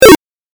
レトロゲーム （105件）
8bitキャンセル3.mp3